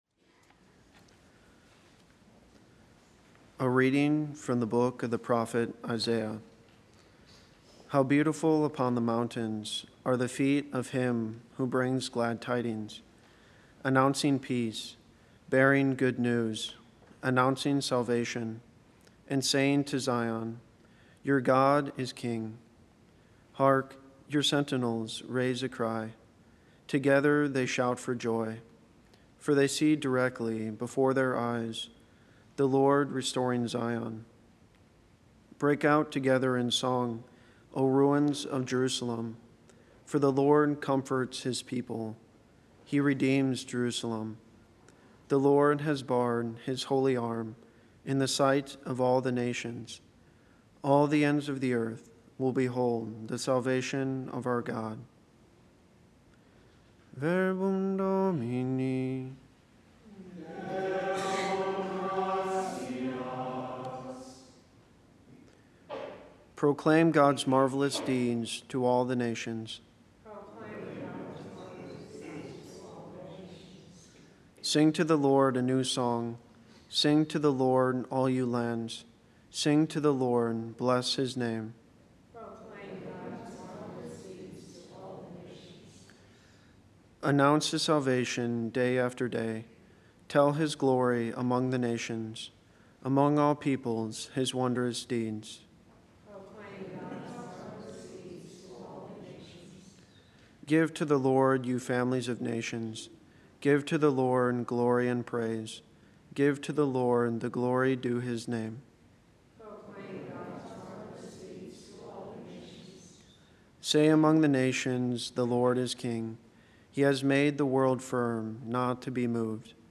Readings, Homily and Daily Mass